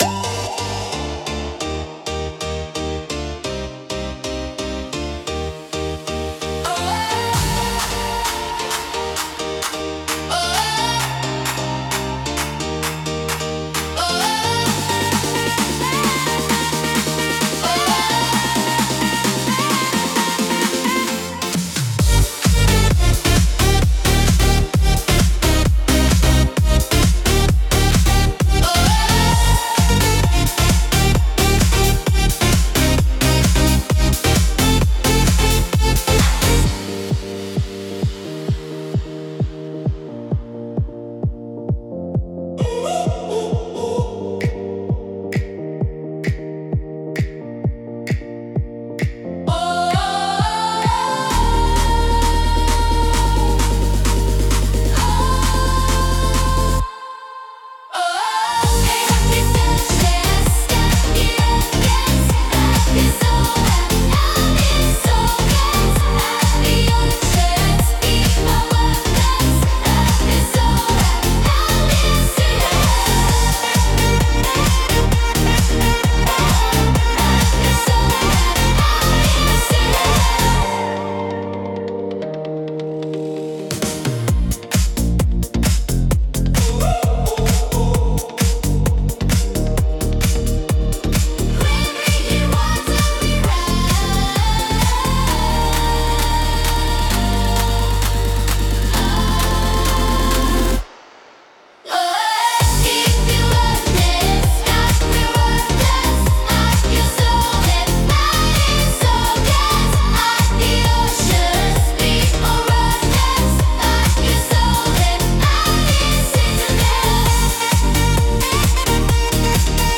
聴く人の気分を高め、集中力とパワーを引き出すダイナミックなジャンルです。